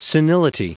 Prononciation du mot senility en anglais (fichier audio)
Prononciation du mot : senility